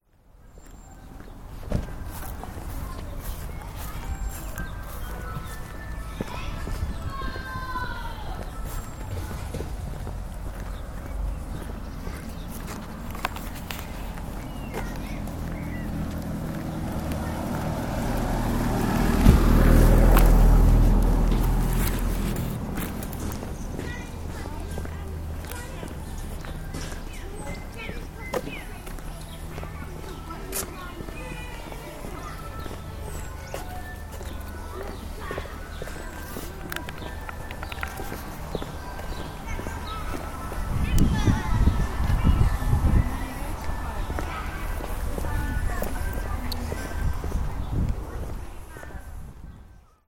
Ice Cream van and play in Bicker
Ice cream van moves through the village and children play in the park